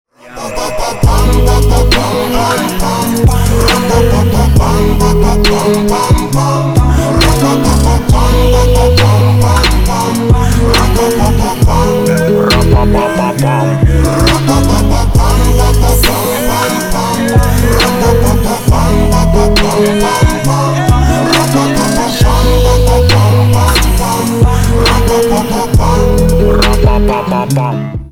Рэп рингтоны
Хип-хоп , Качающие